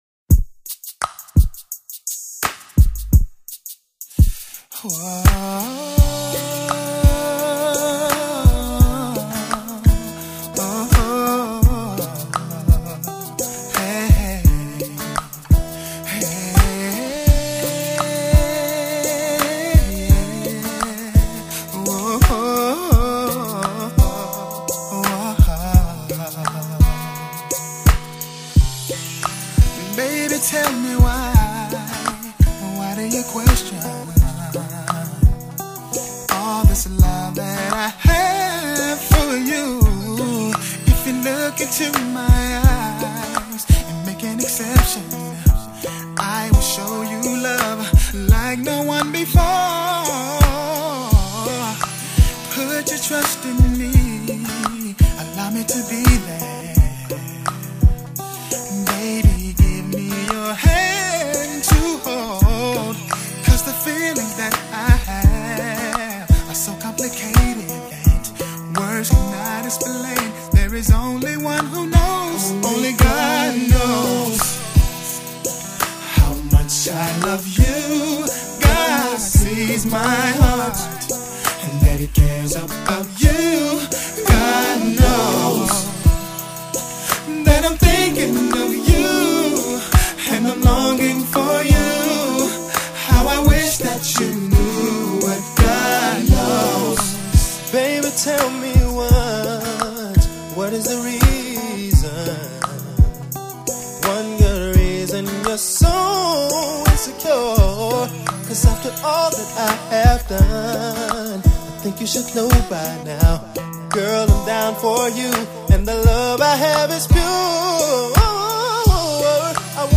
音乐类型：西洋音乐
天籁四部合声主轴+跃动节奏感Hip-Hop乐符+ 时尚舞感的新潮搭配
强烈的节奏蓝调节奏与魅力十足的合声或独唱